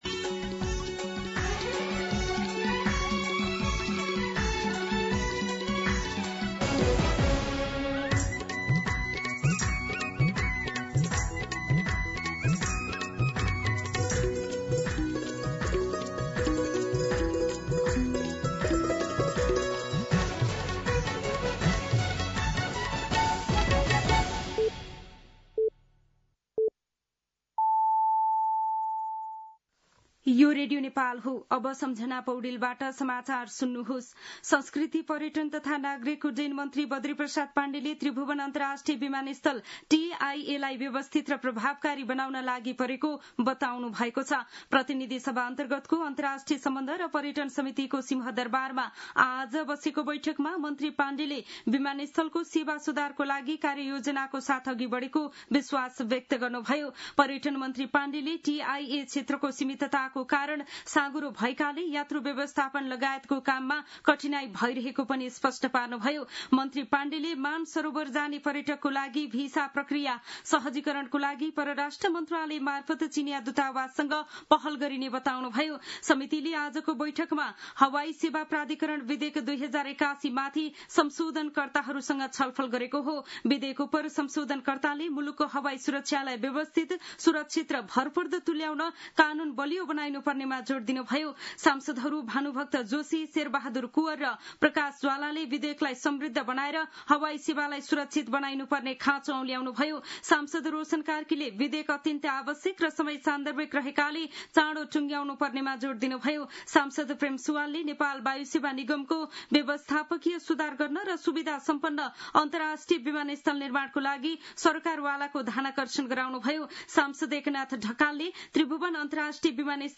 दिउँसो १ बजेको नेपाली समाचार : २२ असार , २०८२